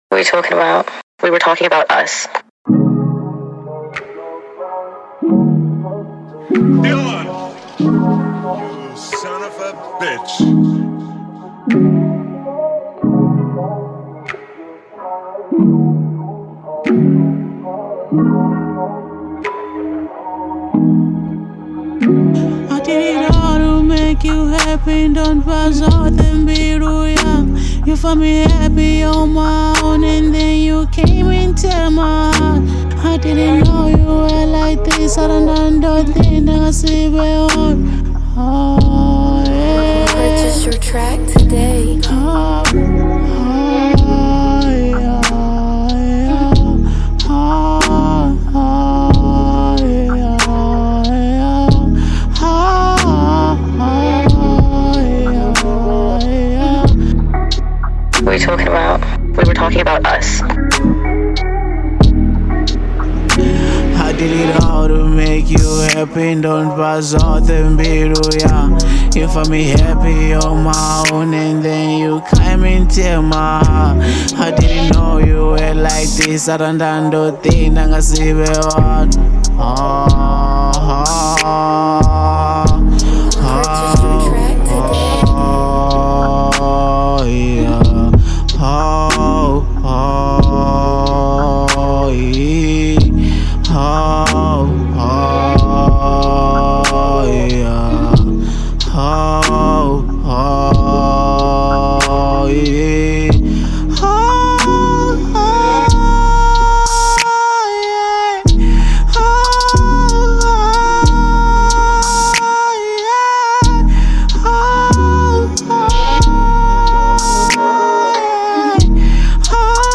02:43 Genre : Venrap Size